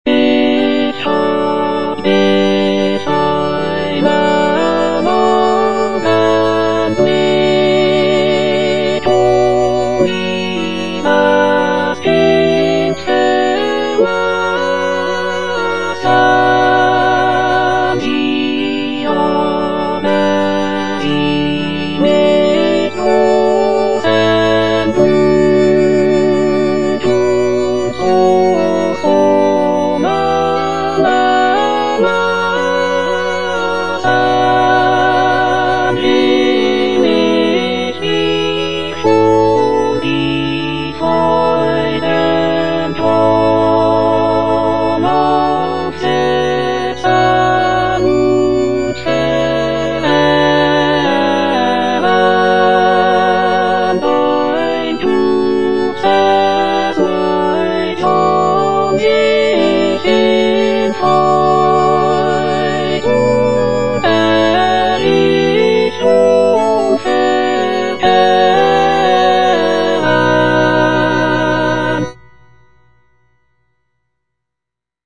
Choralplayer playing Cantata